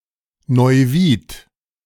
Neuwied (German pronunciation: [nɔʏˈviːt]
De-Neuwied.ogg.mp3